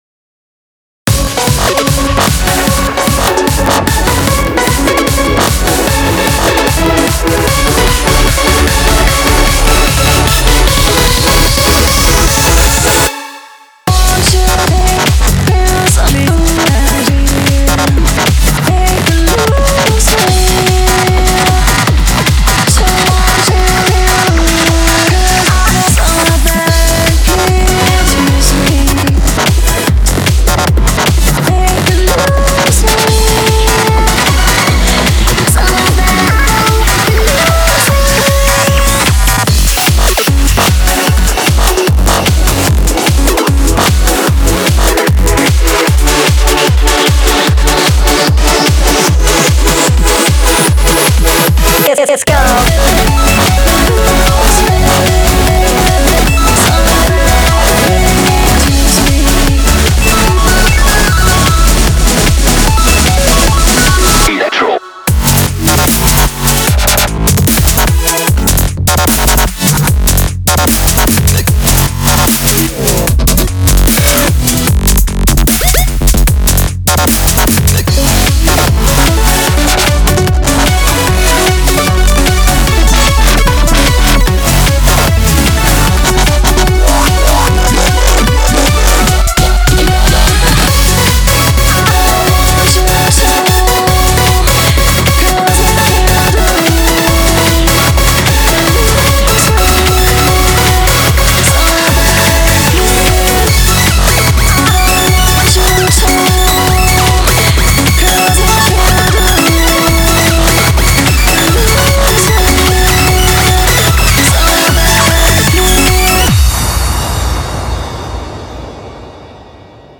BPM75-150
Audio QualityPerfect (High Quality)
Genre: ORBITALICTRO.